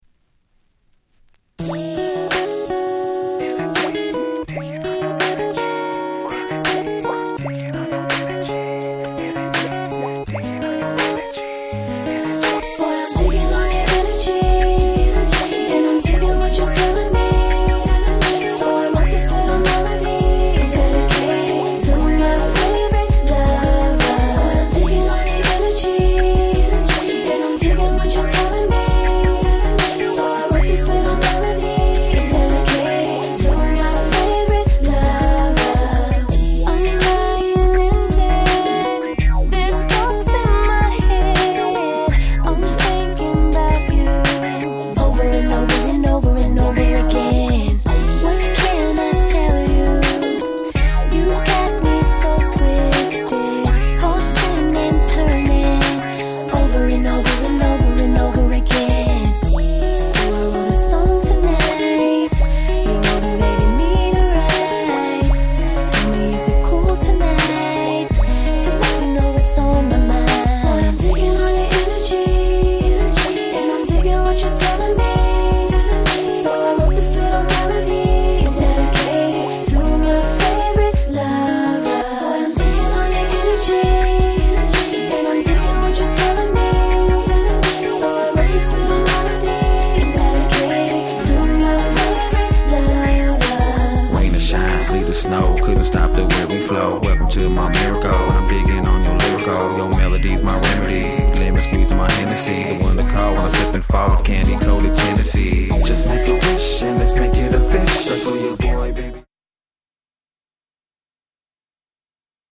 00's キラキラ系